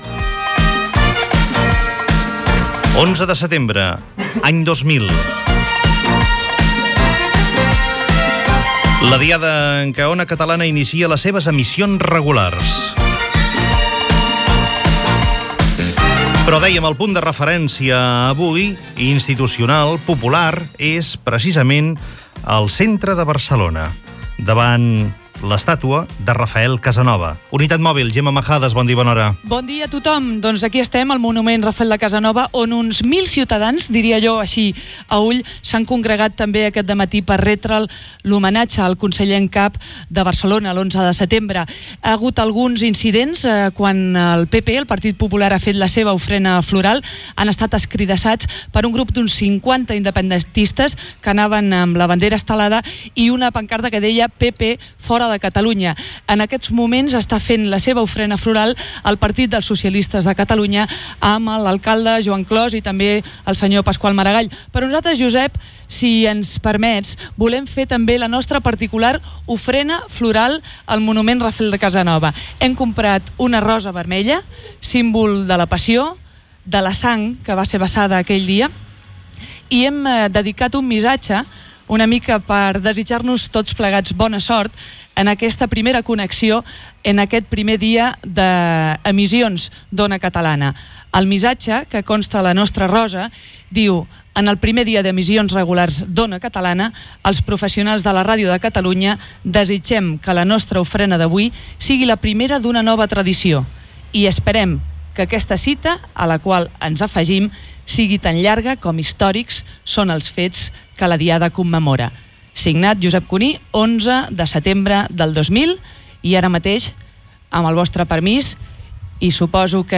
Connexió amb la unitat mòbil que està al monument del conseller en cap Rafel Casanova, a Barcelona. Ofrena floral del programa.
Info-entreteniment